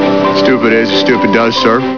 The famous saying from the movie Forrest Gump with Tom Hanks.